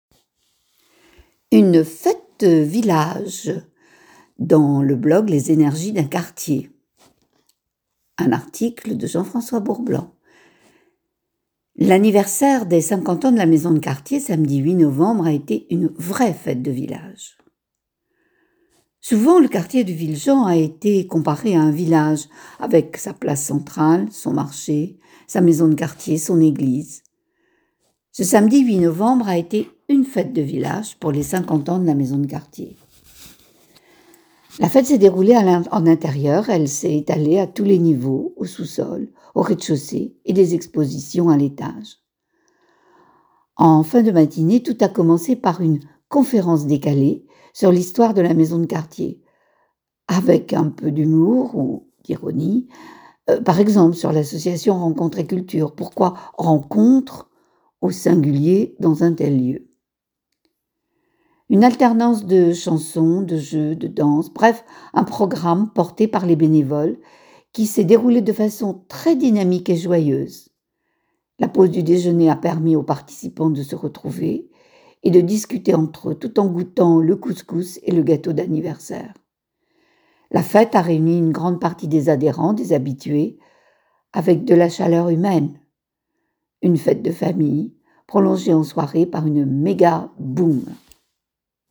Une fête de village
Ce samedi 8 novembre a été une fête de village, pour les 50 ans de la Maison de quartier.. La fête s'est déroulée en intérieur : elle s'est étalée à tous les niveaux, au sous-sol, au rez-de-chaussée et des expositions à l'étage.
Une alternance de chansons, de jeux, de danses, bref un programme, porté par les bénévoles qui s'est déroulé de façon très dynamique et joyeuse.